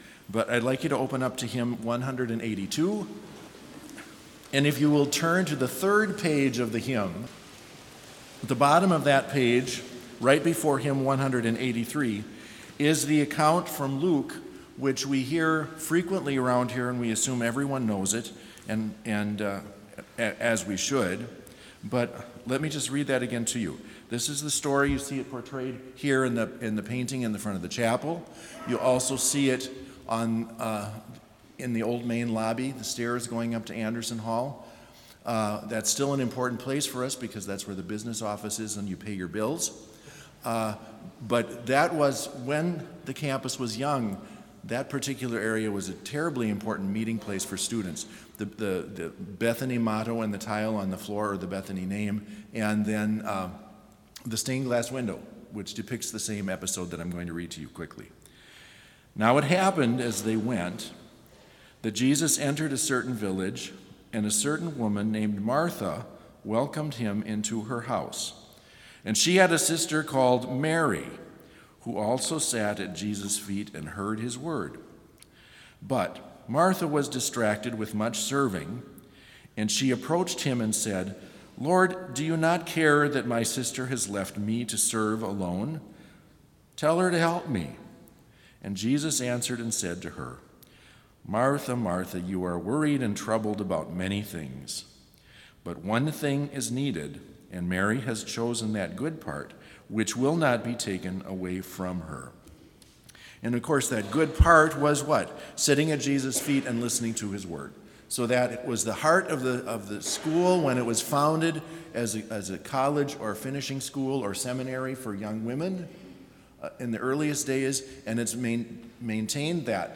Complete service audio for Chapel - August 27, 2021